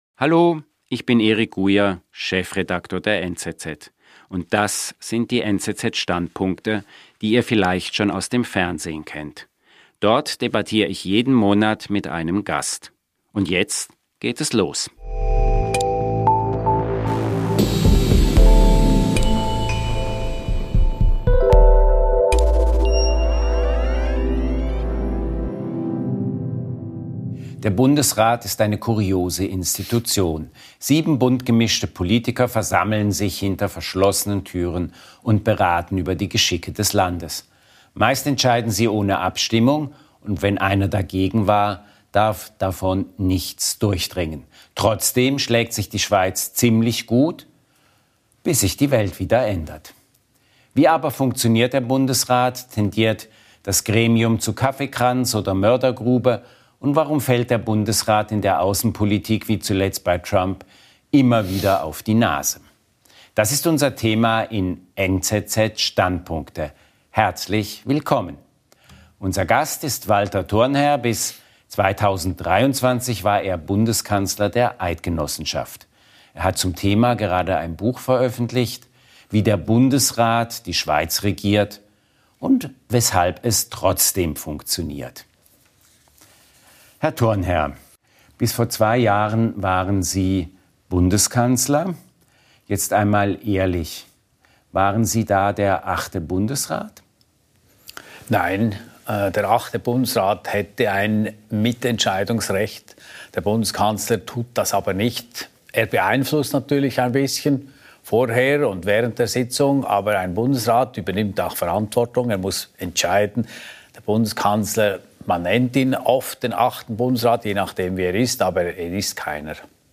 Ähnlich scheint es jüngst bei der neuen amerikanischen Zollpolitik gelaufen zu sein. In der neuen Podcast-Folge von «NZZ Standpunkte» spricht der NZZ-Chefredaktor Eric Gujer mit dem ehemaligen Schweizer Bundeskanzler Walter Thurnherr.